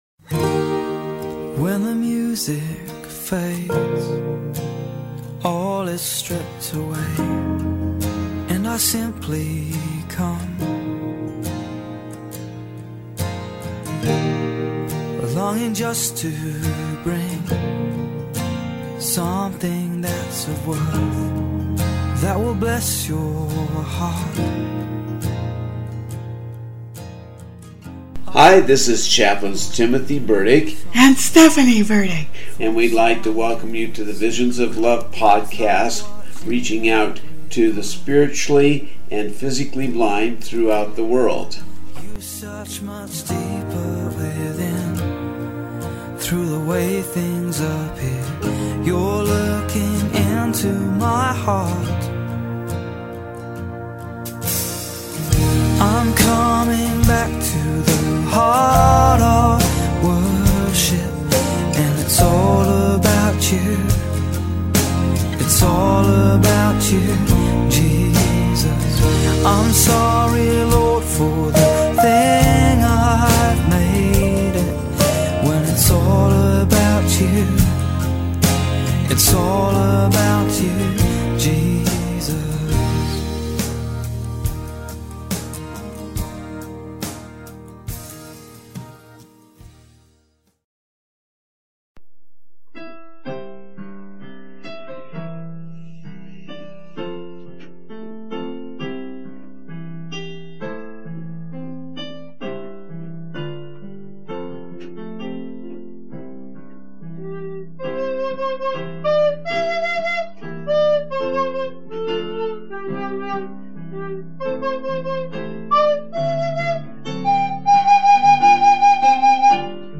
We're back with some more music and the second part of our Independent Church of India podcast.